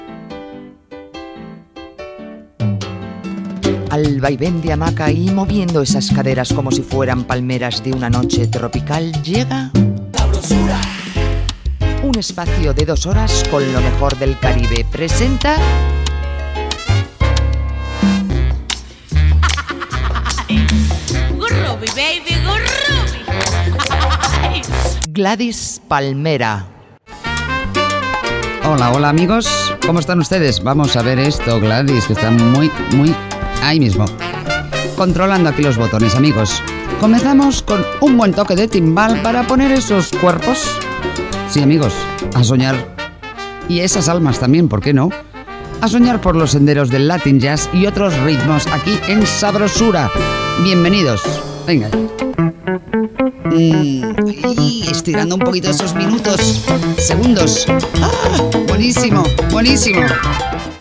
Llatina
Presentació del programa
Musical